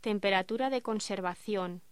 Locución: Temperatura de conservación
voz